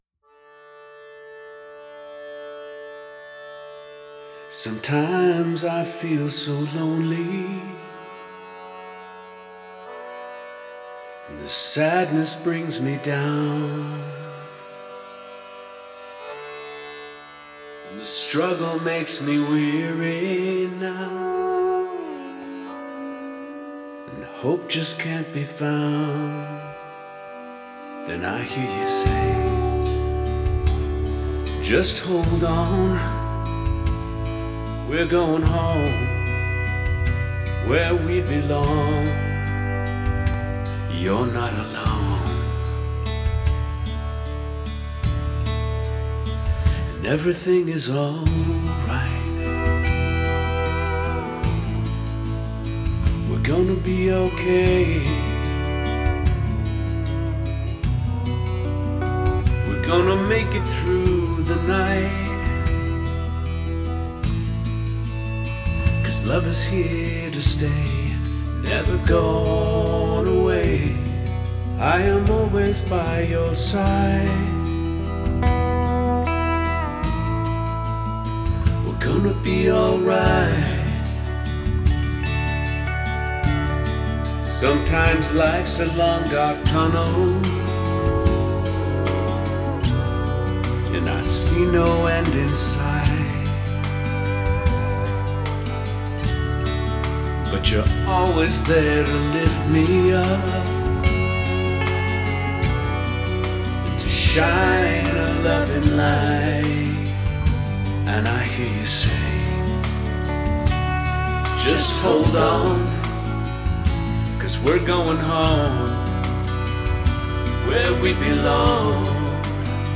Guitar and Keys